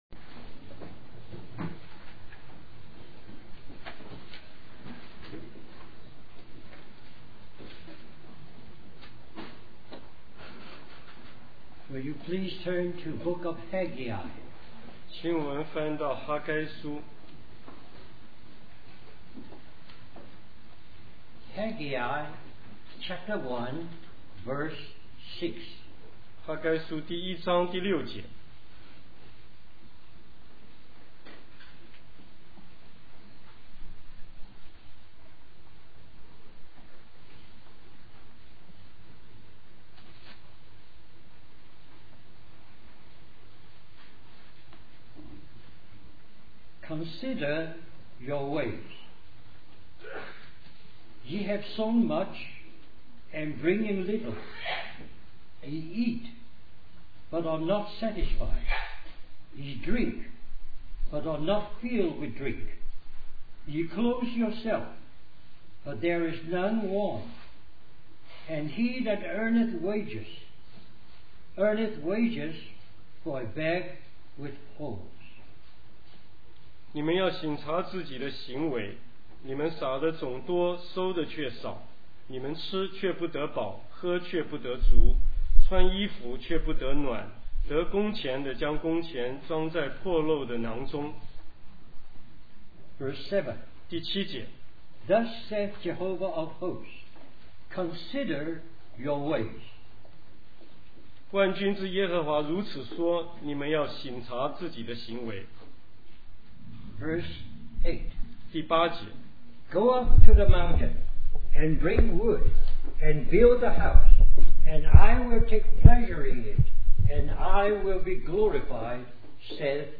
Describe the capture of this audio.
Special Conference For Service, Australia